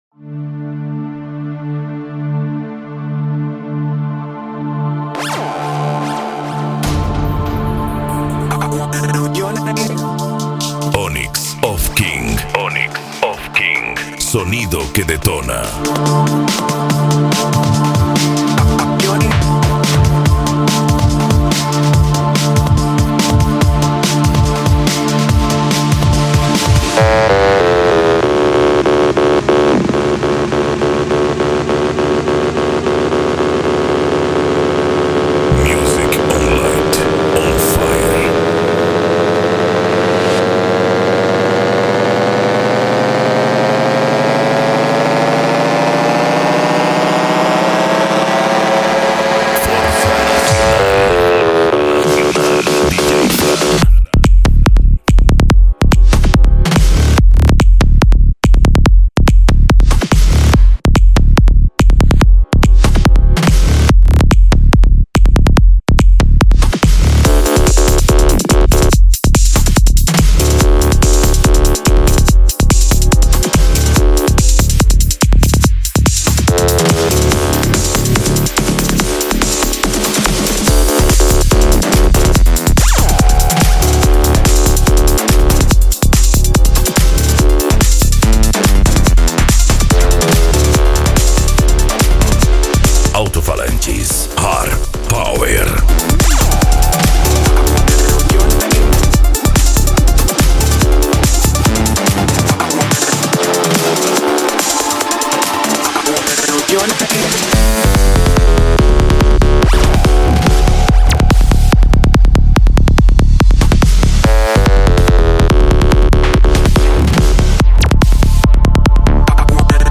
Bass
Modao
Musica Electronica
Remix